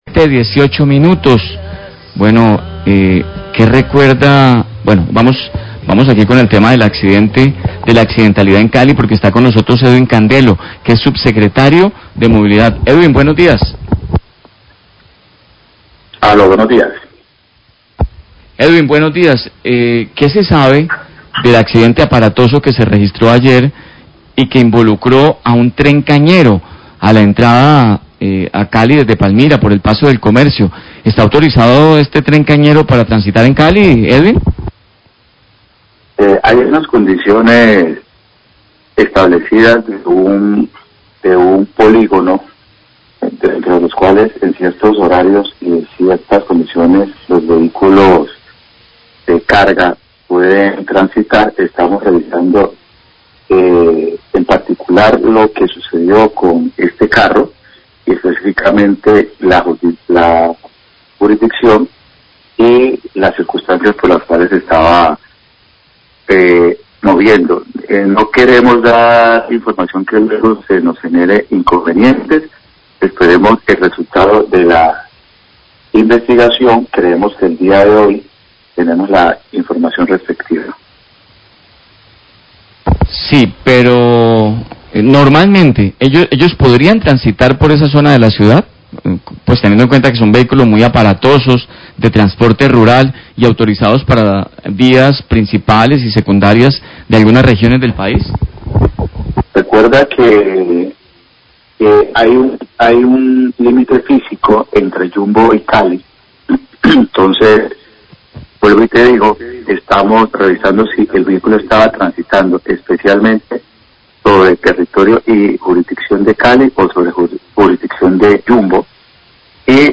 Reporte de movilidad por accidente de tren cañero, 719am